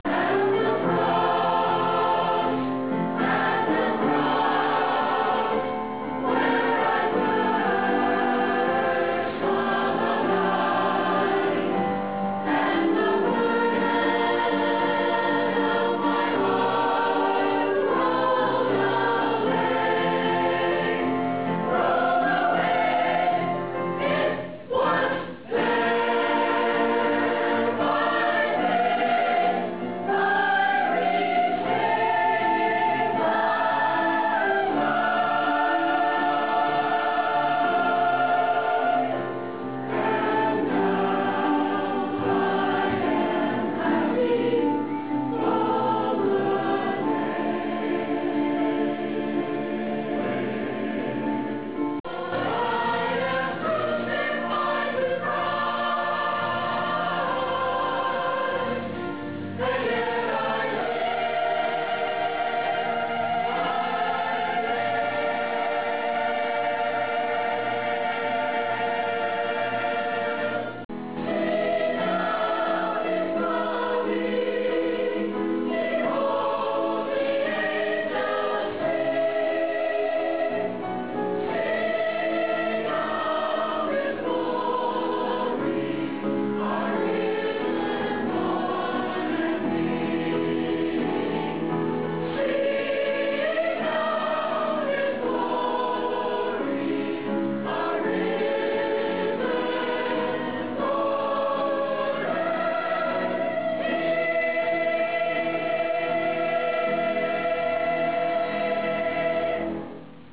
Resurrection Sunday Worship
Music Clips: Three Choir Song Excerpts